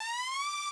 FX Sizzle.wav